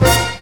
JAZZ STAB 12.wav